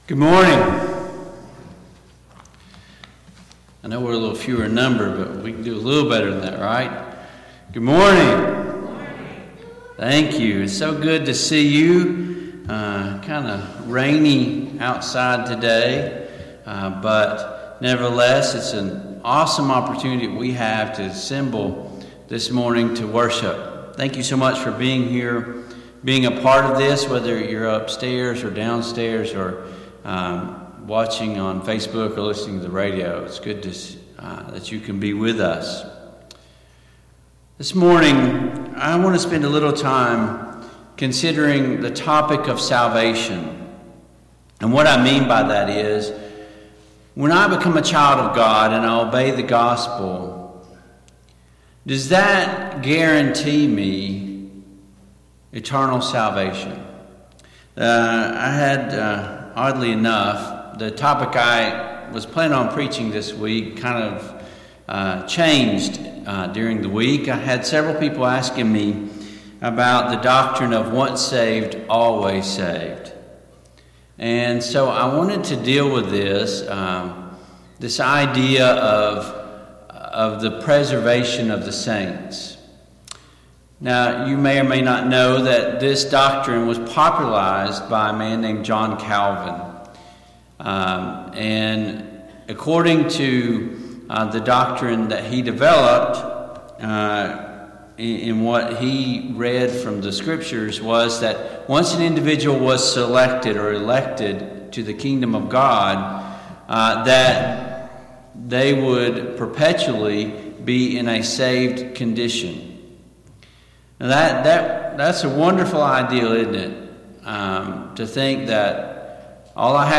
Service Type: AM Worship Topics: Falling from Grace , Repentence , Salvation , The Grace of God